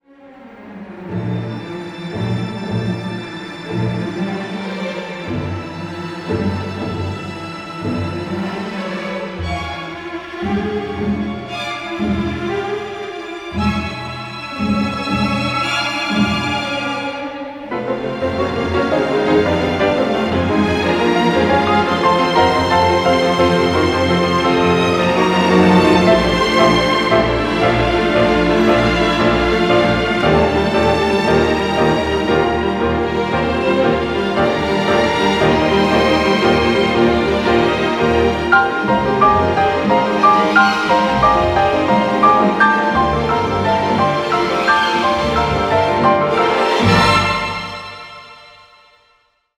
recorded at Abbey Road Studios